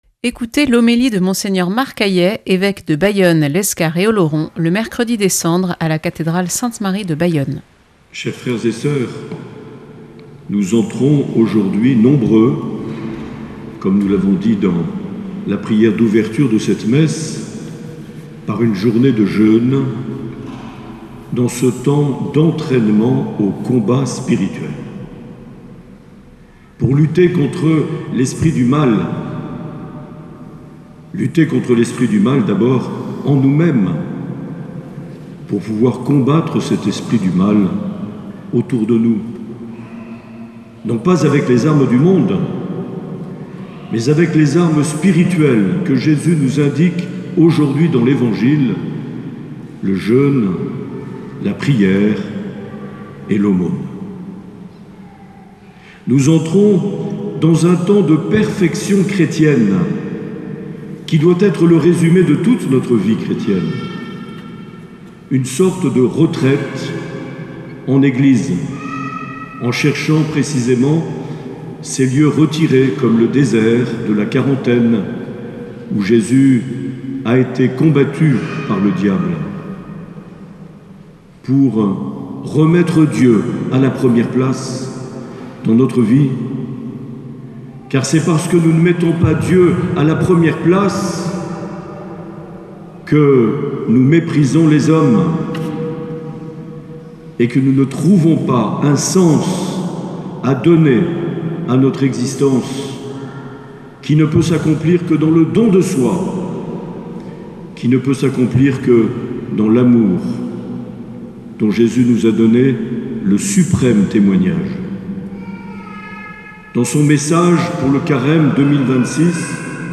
18 février 2026 : Messe des Cendres - Cathédrale de Bayonne
Homélie de Mgr Marc Aillet